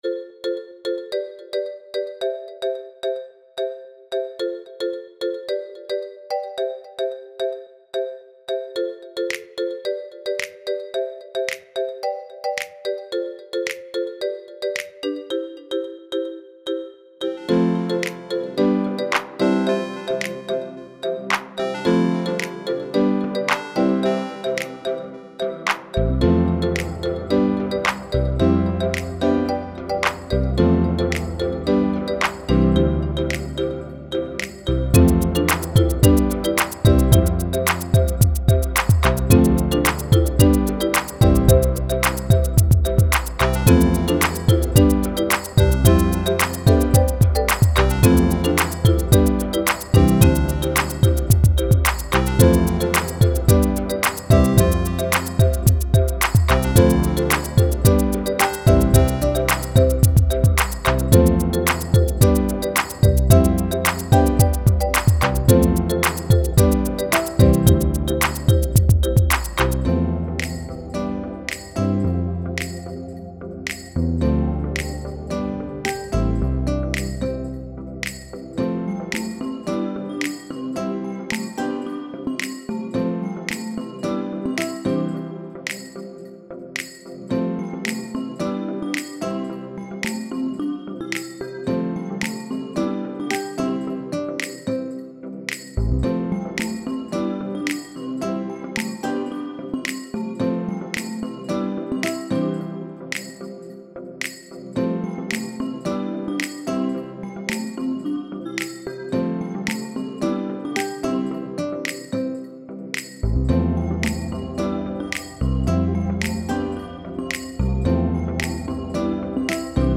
winter, festive, Christmas